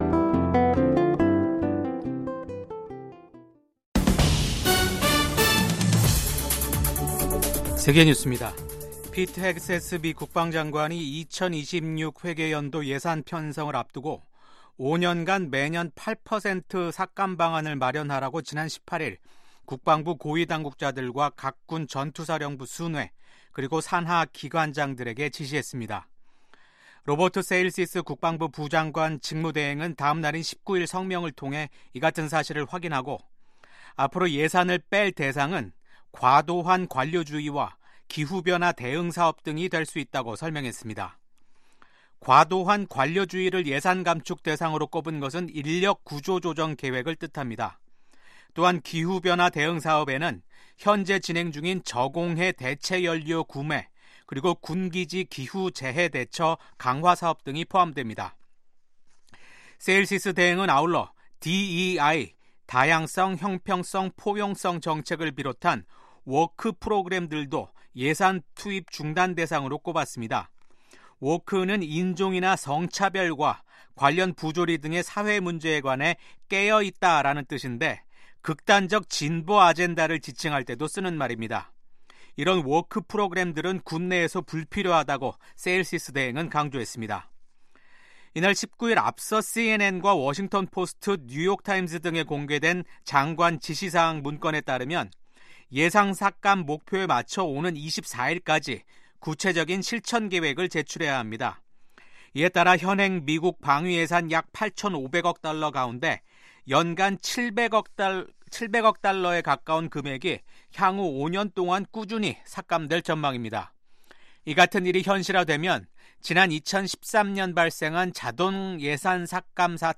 VOA 한국어 아침 뉴스 프로그램 '워싱턴 뉴스 광장'입니다. 미국 정부는 중국이 타이완 해협의 평화와 안정을 해치고 있다는 점을 지적하며 일방적 현상 변경에 반대한다는 입장을 확인했습니다. 미국의 ‘핵무기 3축’은 미국 본토에 대한 북한의 대륙간탄도미사일 공격을 효과적으로 억지할 수 있다고 미국 공군 소장이 말했습니다.